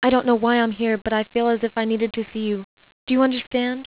• This version contains two additional voice clips: